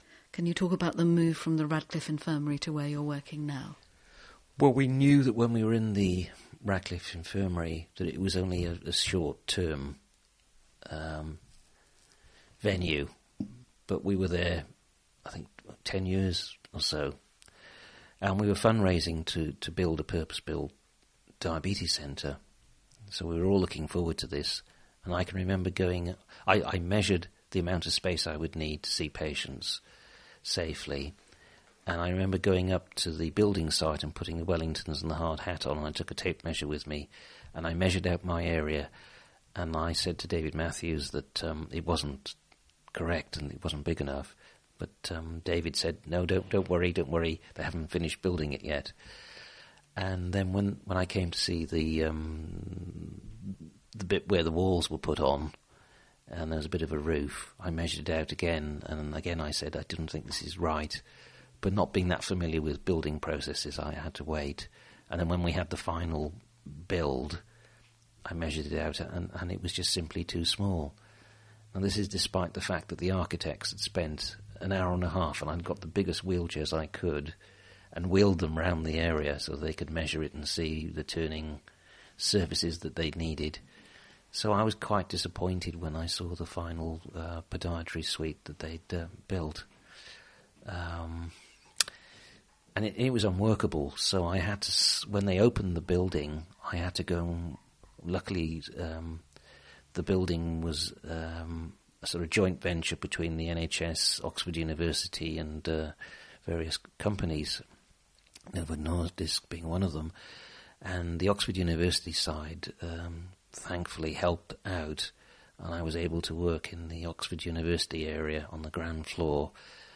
Available interview tracks